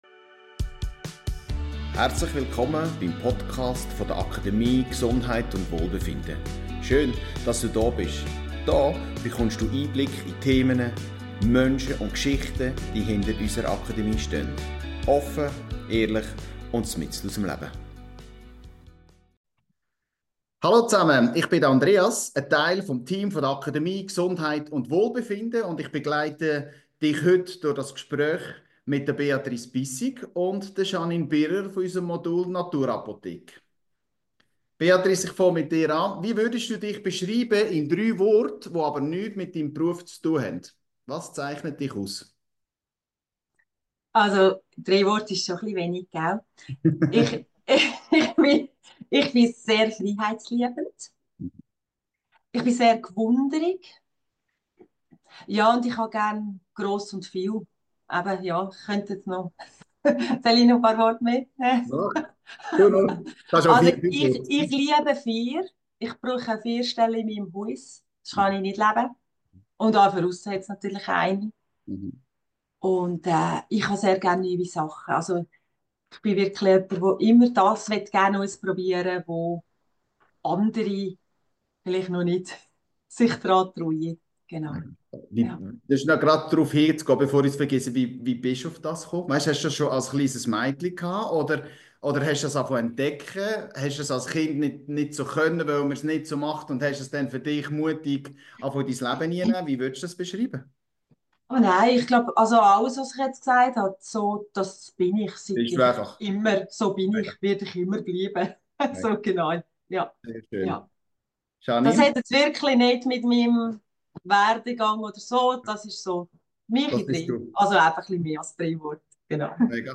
Ein Gespräch über puristische Rezepturen, gelebte Pflanzenliebe, die Kunst des Räucherns und das Vertrauen, auch ohne Konservierungsstoffe Kraftvolles zu erschaffen.